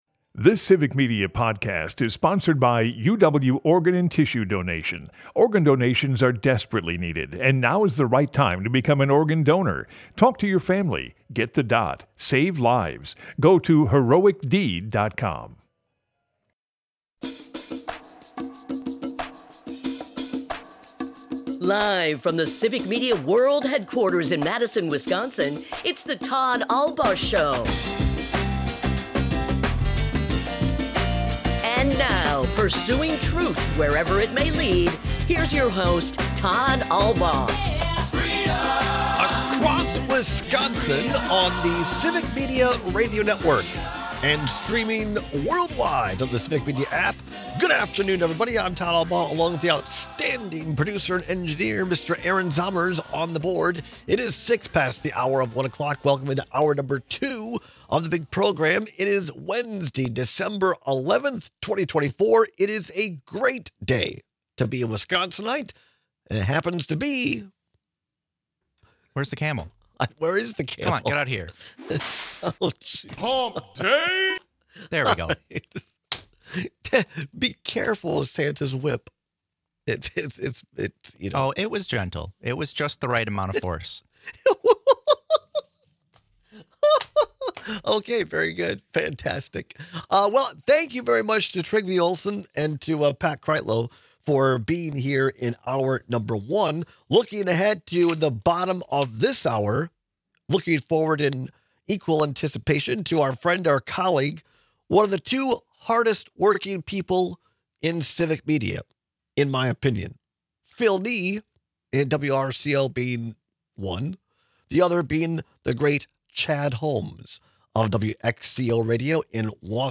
We take calls and texts with your opinions.